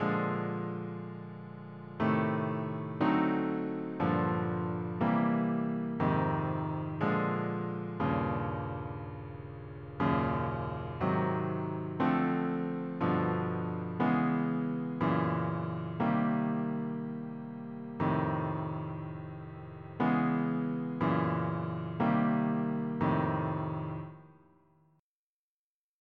It uses bebop harmony to create as much harmonic motion as possible.
Bird Blues chord progression